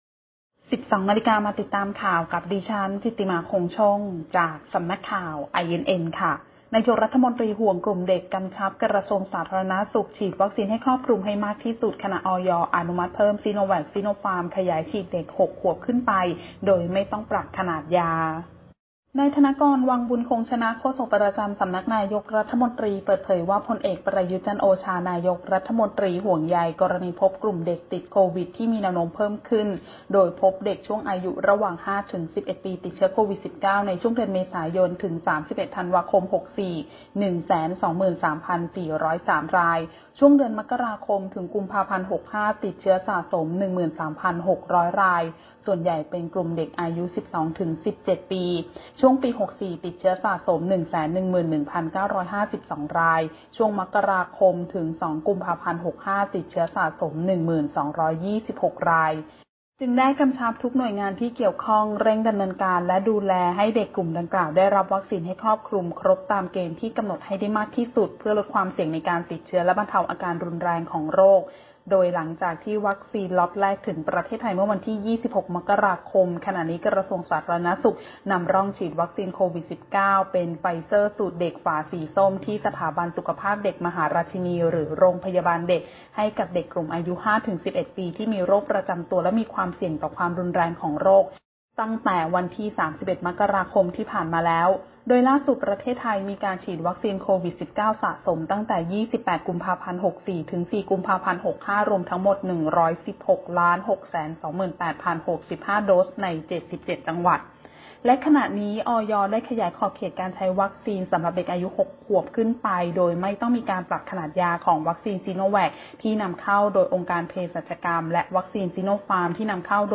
คลิปข่าวต้นชั่วโมง
ข่าวต้นชั่วโมง 12.00 น.